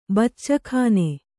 ♪ baccakhāne